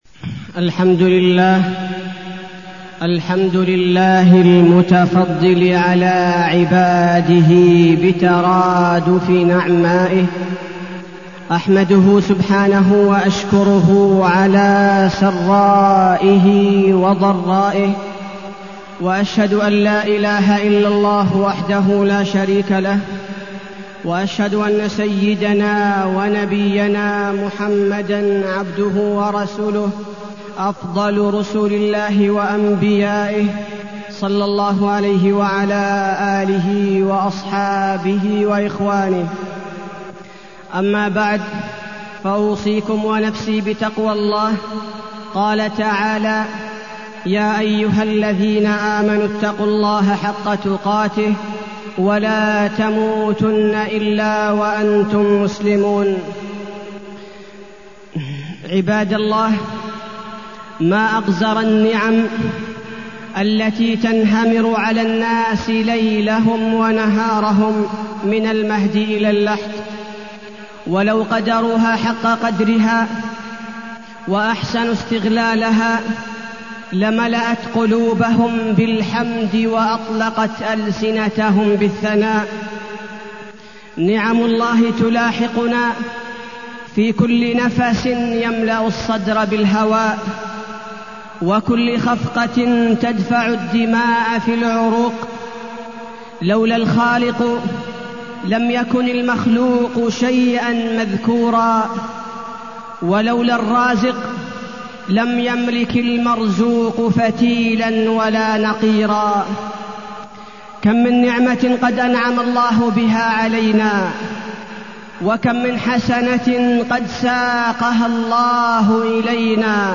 تاريخ النشر ٢ جمادى الأولى ١٤٢٠ هـ المكان: المسجد النبوي الشيخ: فضيلة الشيخ عبدالباري الثبيتي فضيلة الشيخ عبدالباري الثبيتي شكر النعم